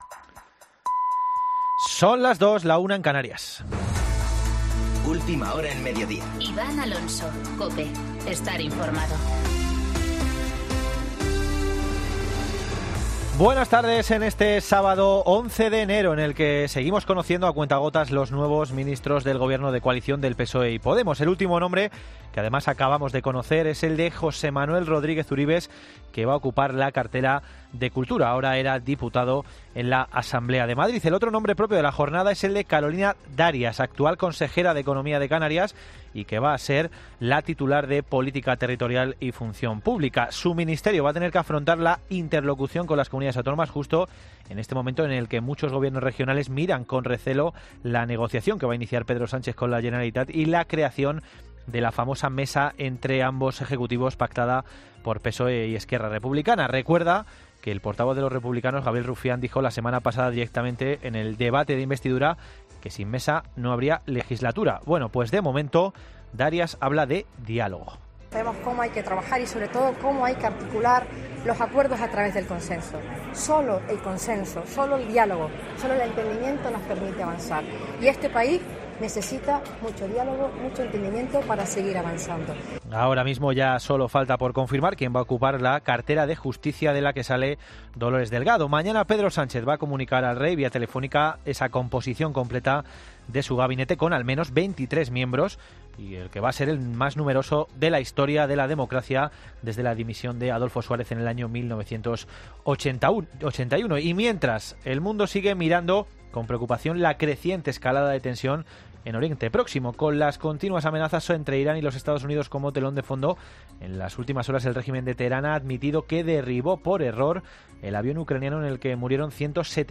Boletín de noticias COPE del 11 de enero de 2020 a las 14.00 horas